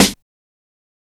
Snare Groovin 4.wav